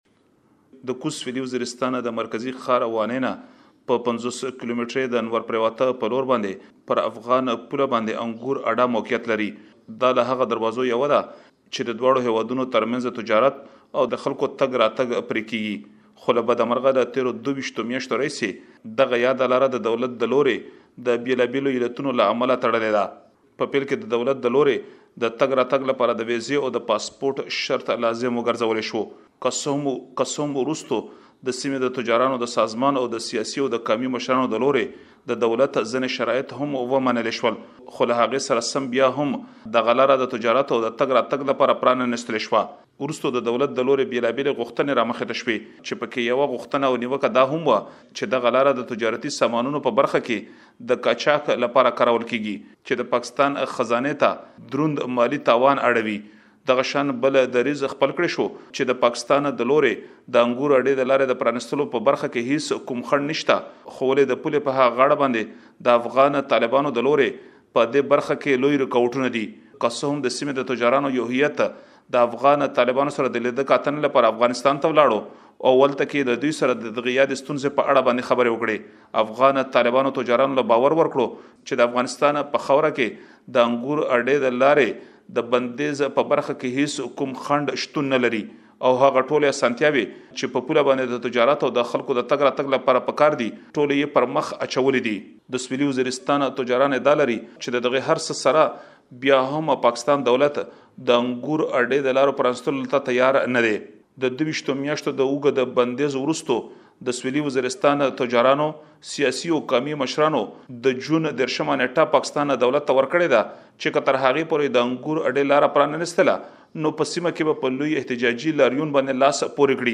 رپوټ